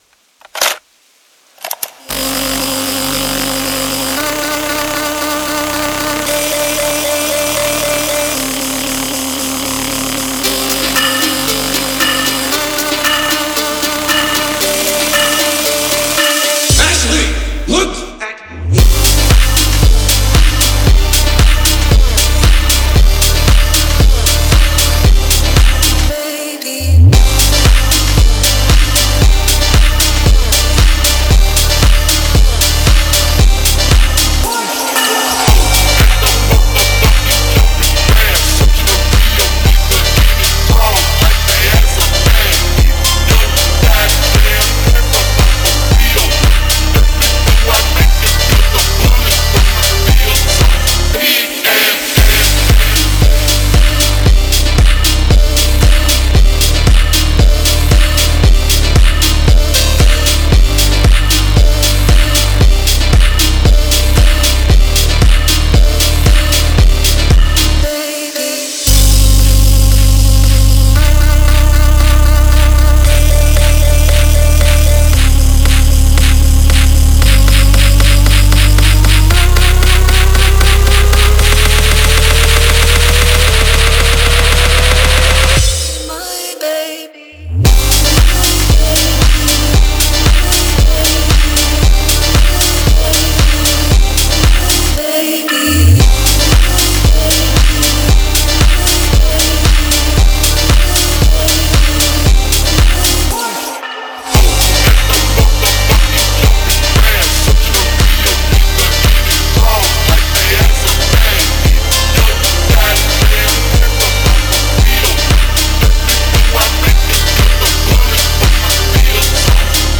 Фонк музыка